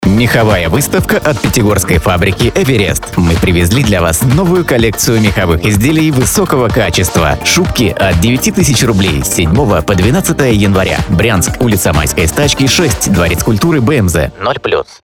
Стартовало размещение рекламы на радиостанции "Ретро FM" мехового дома "Эверест" в г. Брянске.